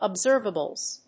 /ʌˈbzɝvʌbʌlz(米国英語), ʌˈbzɜ:vʌbʌlz(英国英語)/